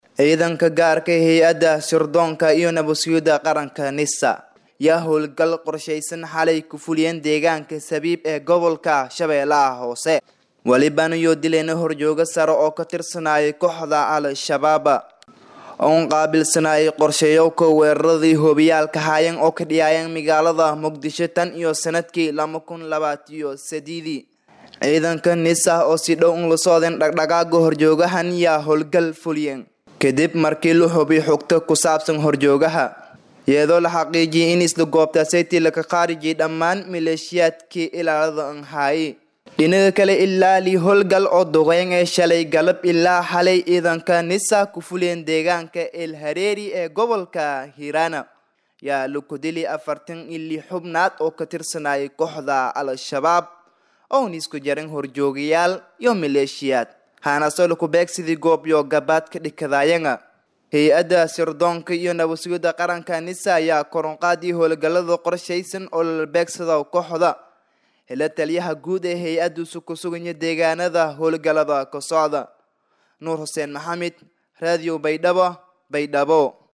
Halkan ka dhageyso warbixin la xiriirta dilka horjoogaha.